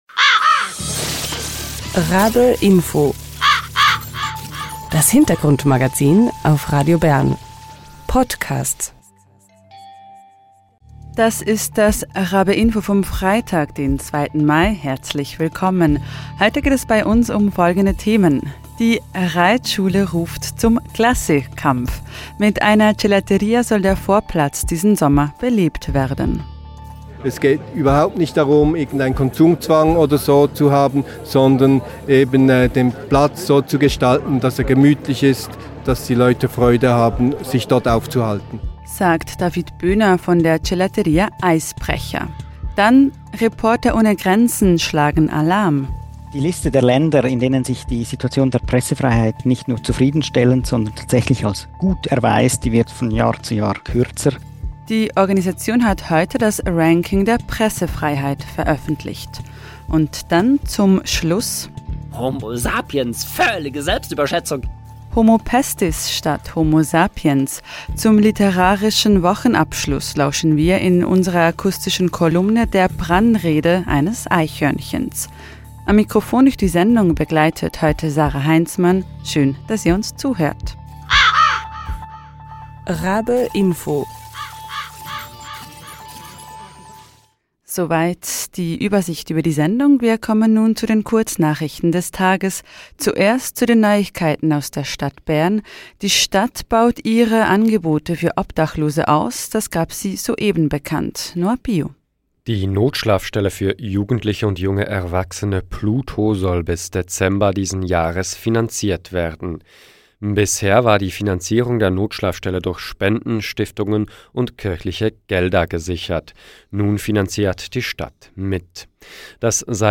In unserer akustischen Kolumne lauschen wir der flammenden Brandrede eines Eichhörnchens.